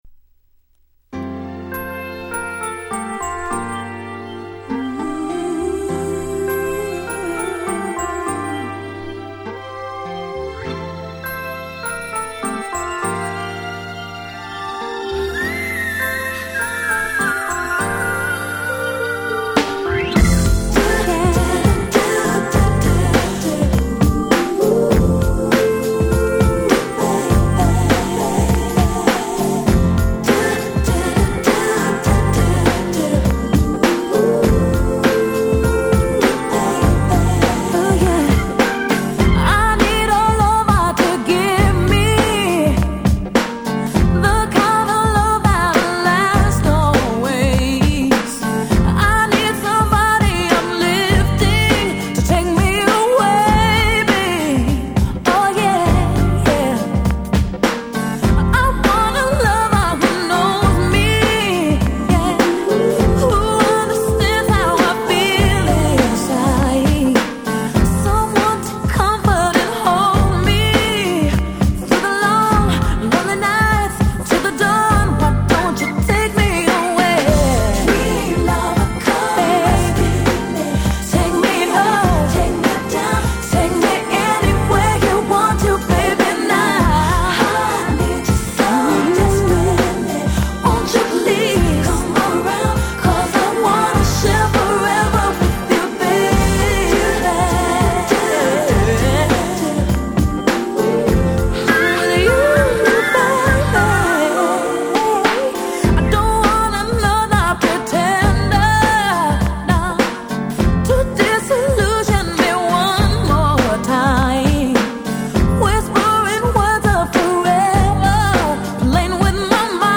93' Super Hit R&B !!